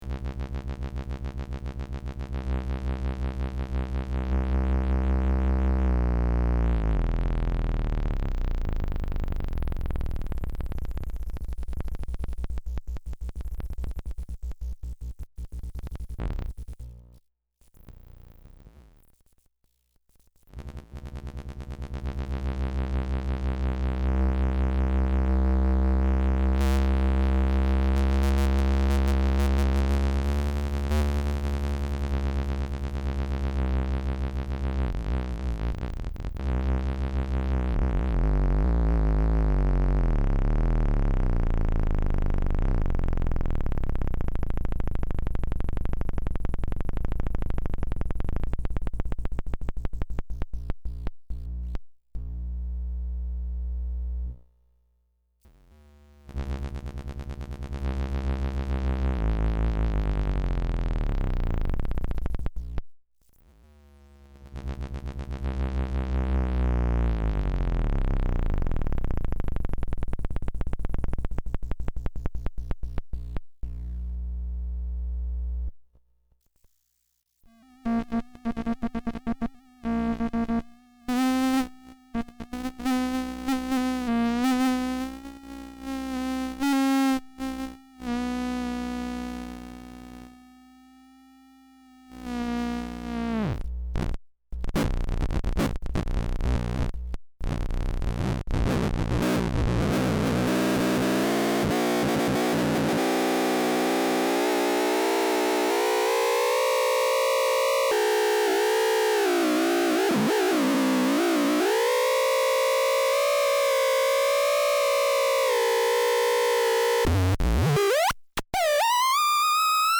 On Saturday the 18th of July, the first El MuCo instrument was completed and taken for her maiden voyage.